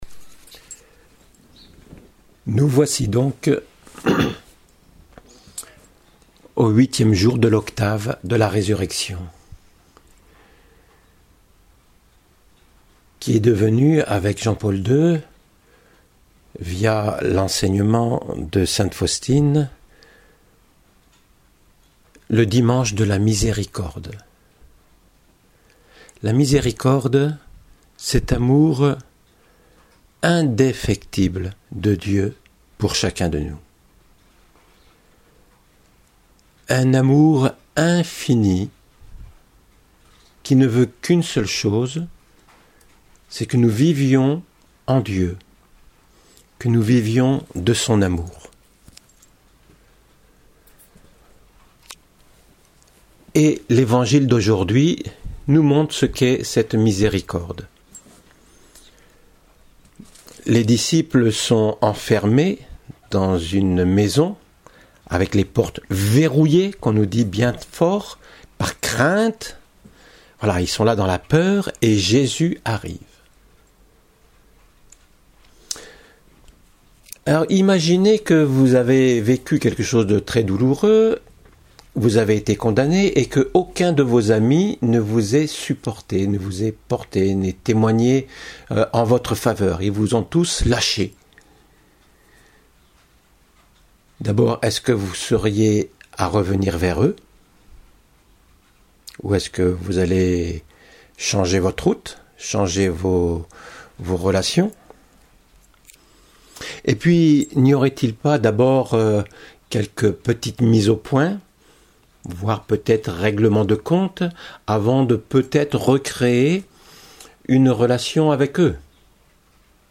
En ce jour de la Miséricorde de Dieu, voici deux versions d’homélie, approfondissant ce mystère fou de l’amour de Dieu pour chacun et chacune d’entre nous.